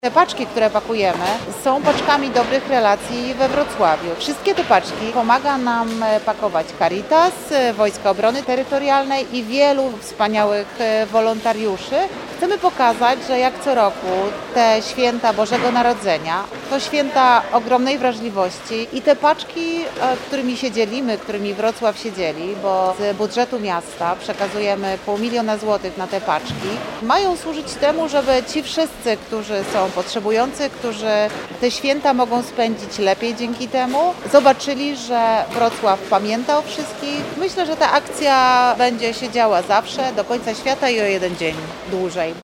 Wiceprezydent Wrocławia Renata Granowska dodaje, że w tym dziele bierze udział wiele podmiotów, a miasto Wrocław jest miejscem budowania dobrych relacji.